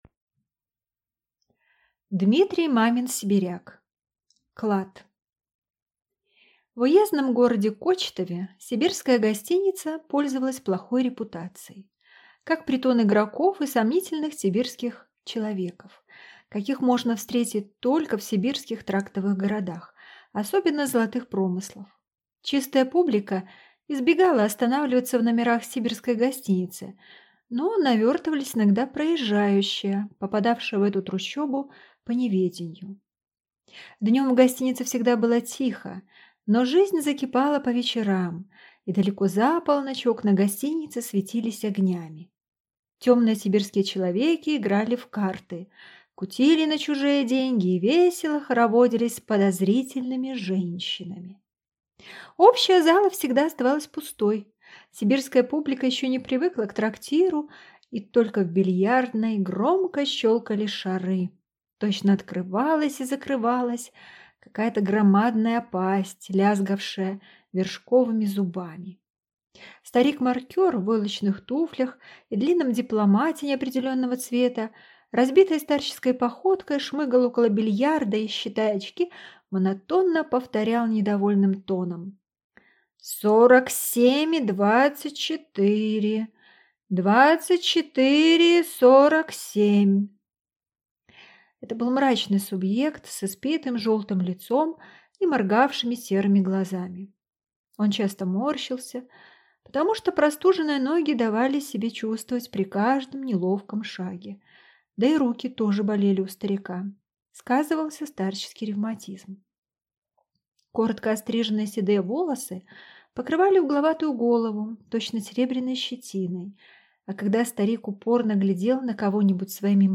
Аудиокнига Клад | Библиотека аудиокниг